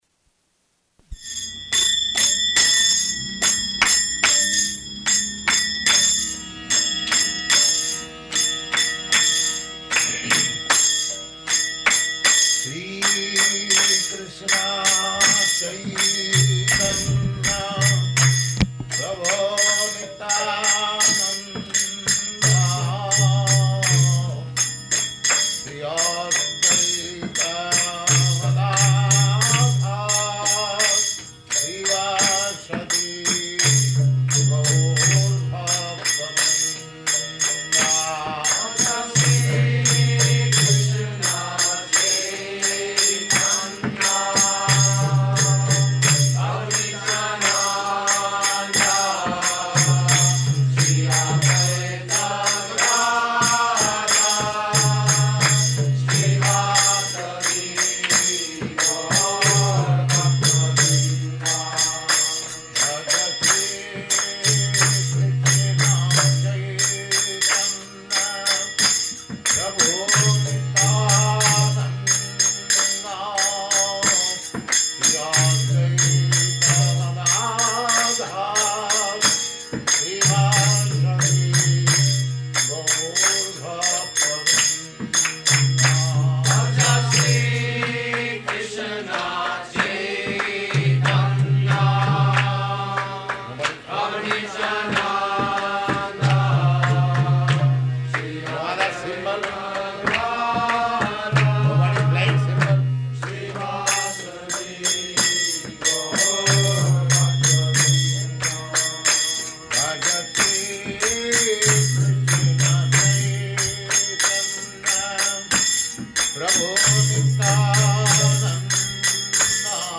Lecture
Lecture --:-- --:-- Type: Lectures and Addresses Dated: December 4th 1968 Location: Los Angeles Audio file: 681204LE-LOS_ANGELES.mp3 Prabhupāda: [ kīrtana ] [ prema-dhvani ] Thank you very much.
[three times with devotees responding]